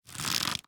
Minecraft Version Minecraft Version snapshot Latest Release | Latest Snapshot snapshot / assets / minecraft / sounds / item / crossbow / quick_charge / quick3_3.ogg Compare With Compare With Latest Release | Latest Snapshot